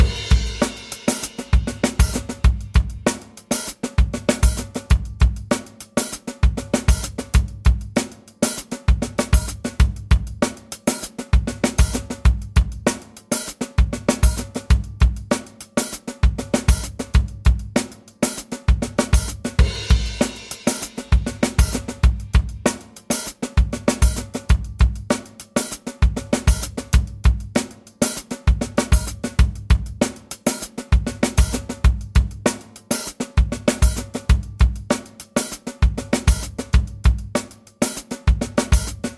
Tag: 绝妙 丛林 跑步者 快乐 音乐 背景声 音乐循环 打击乐器 舞蹈 循环